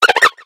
Cries
PANSEAR.ogg